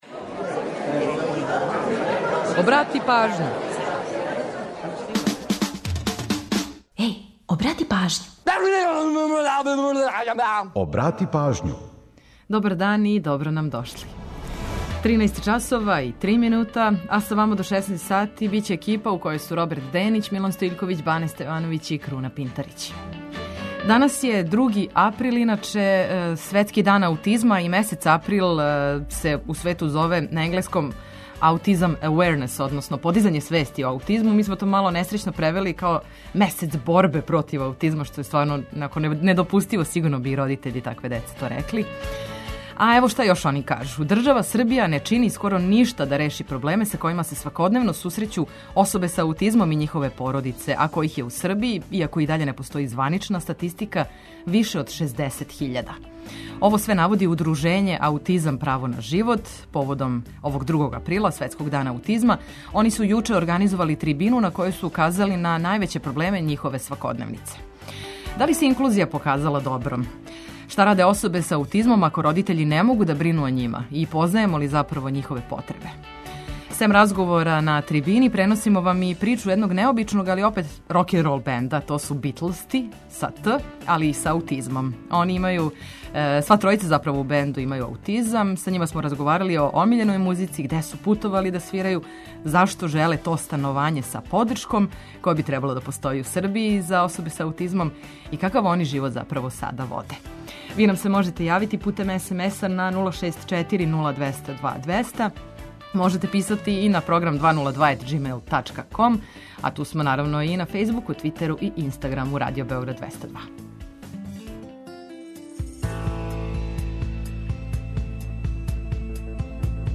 Сем разговора на трибини, преносимо вам и причу једног необичног, а опет тако рокенрол бенда - БИТЛСТИ.